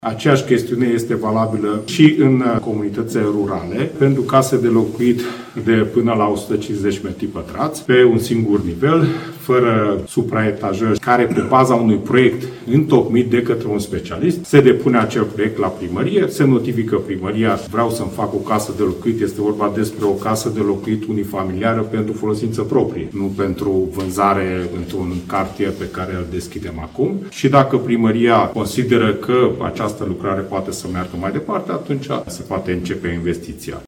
Ministrul Dezvoltării Cseke Attila speră că în acest fel va fi redusă birocrația și a explicat, într-un interviu acordat publicației Alba24, primăria va avea 15 zile la dispoziție pentru a decide dacă este nevoie sau nu de autorizație pentru acea construcție.
Ministrul Dezvoltării, Cseke Attila: „Dacă primăria consideră că această lucrare poate să meargă mai departe, atunci se poate începe investiția”